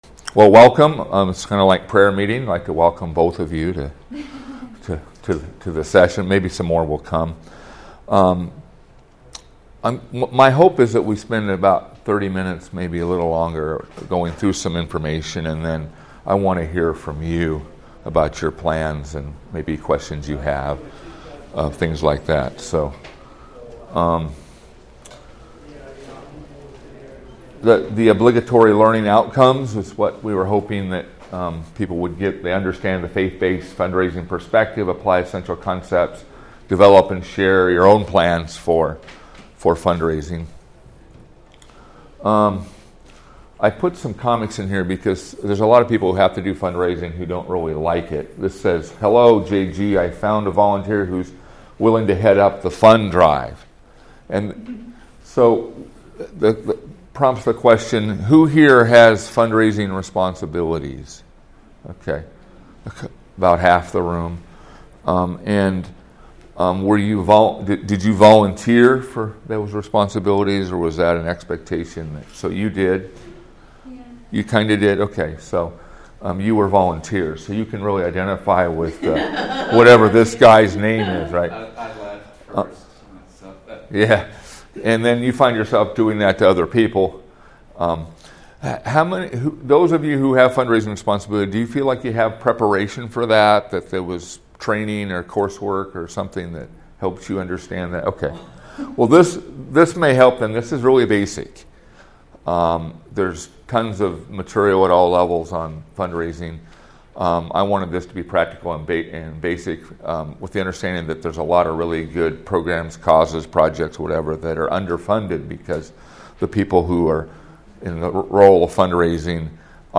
Training format: audio-based